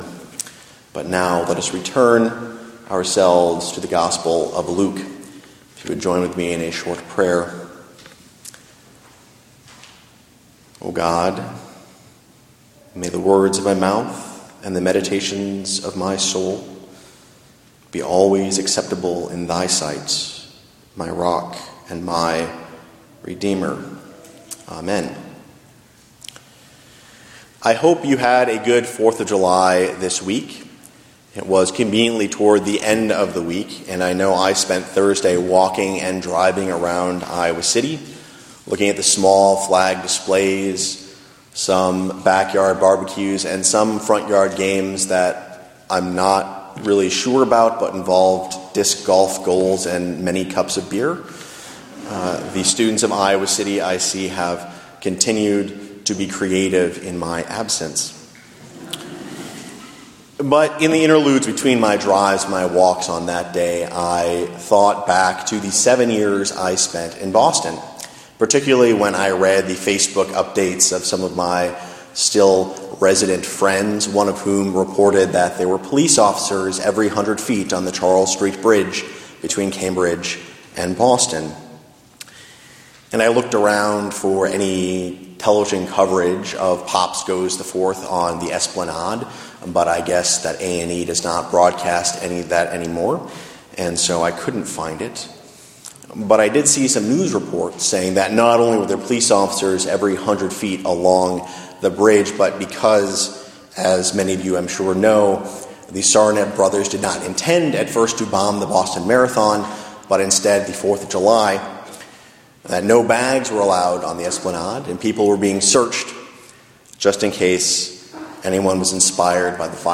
Preached at the Congregational Church UCC in Iowa City, July 7, 2013
Sermon text Luke 10:1-11, 16-20